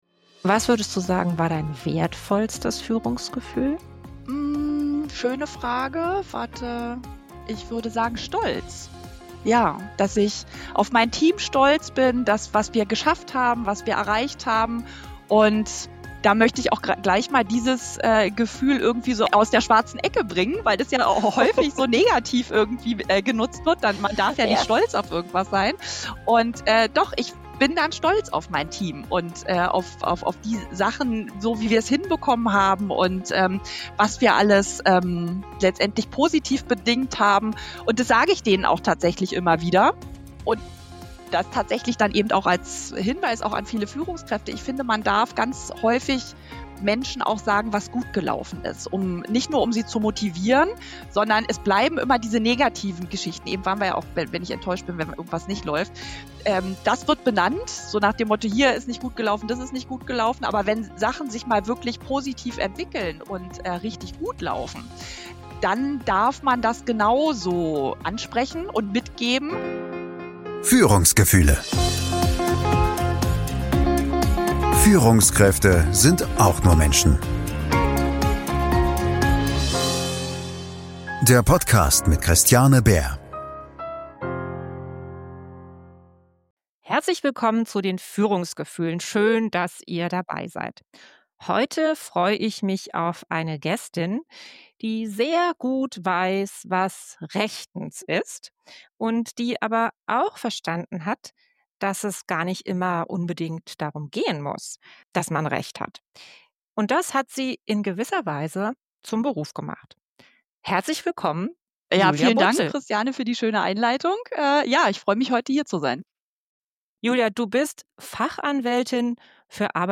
Mitdenken, Freude schenken – Wie Führung mit Gefühl gelingt - Gespräch